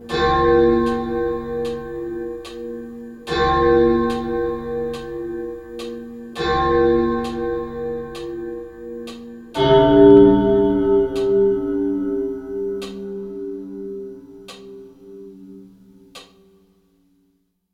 Did you hear that? It’s the bell.
CTS-Church-Bells.wav